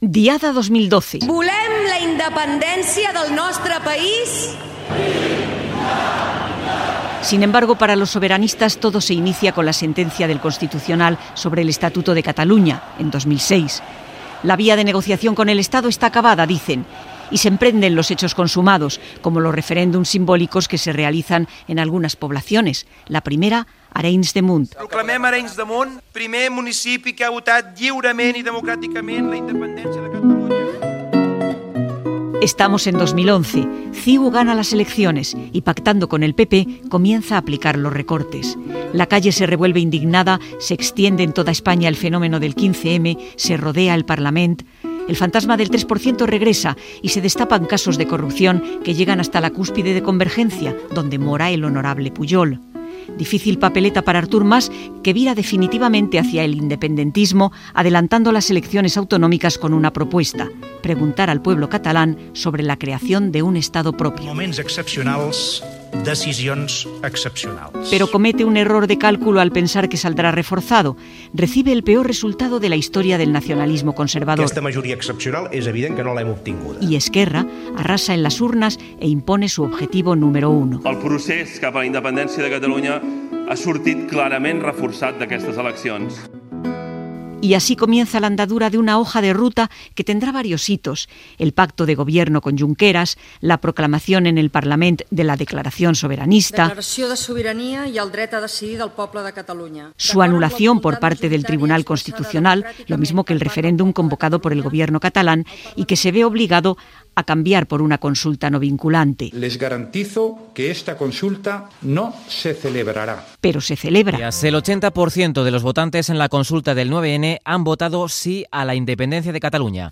Reportatge sobre el procés independentista català des de la Diada Nacional de Catalunya del 2012 al mes de setembre del 2015
Informatiu